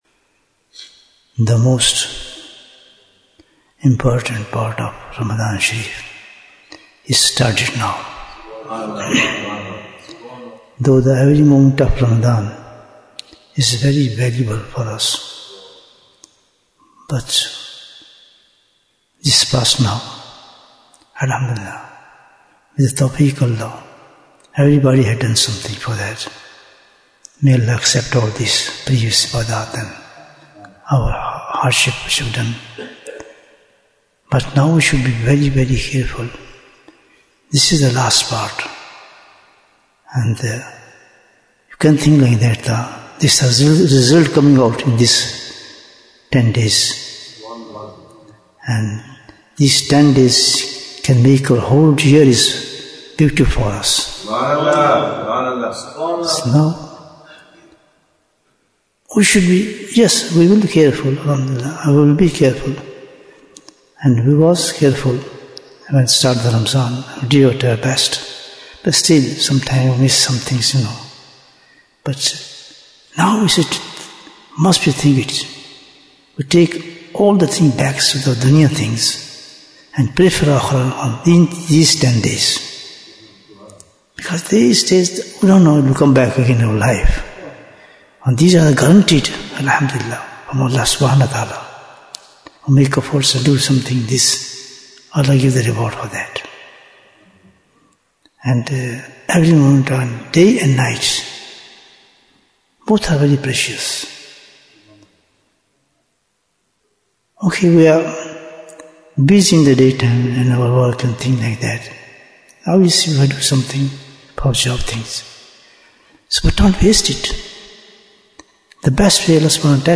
Jewels of Ramadhan 2026 - Episode 27 Bayan, 16 minutes10th March, 2026